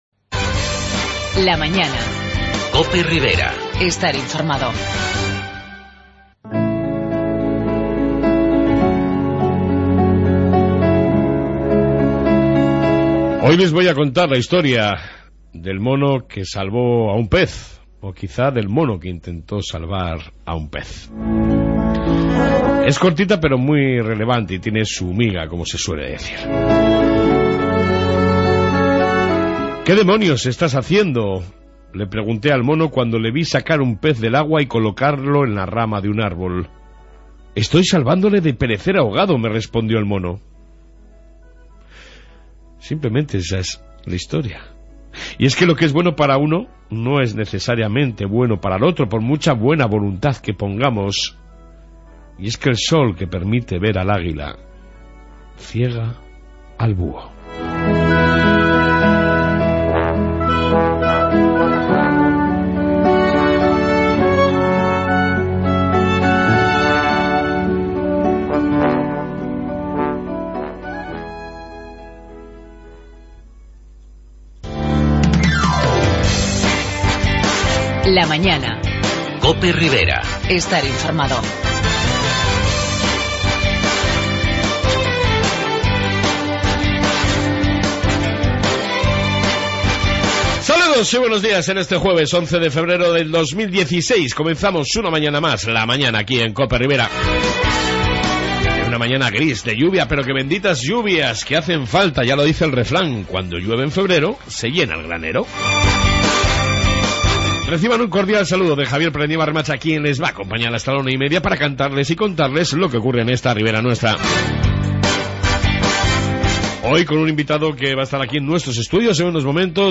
AUDIO: En esta 1 Parte Reflexión diaria, Informe diario Policía Municipal y Entrevista con el candidato a la Federación Navarra de Futbol...